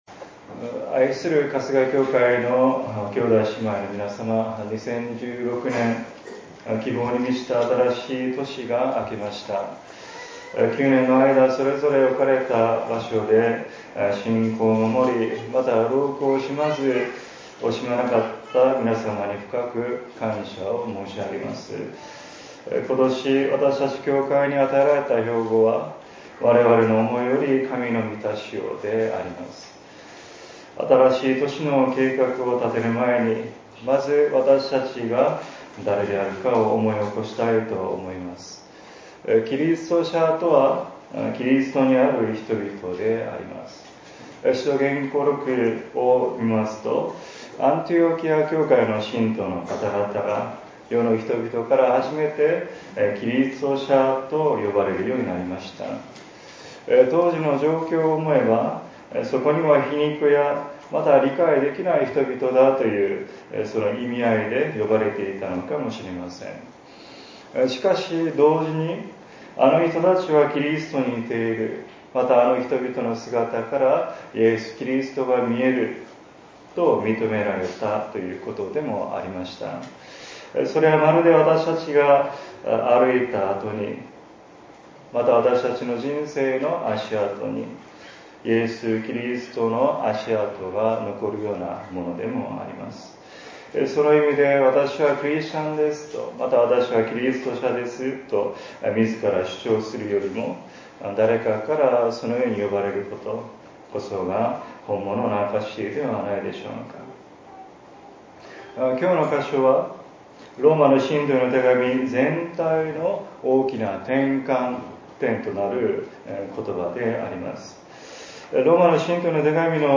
説 教 「我々の思いより、神の満たしを」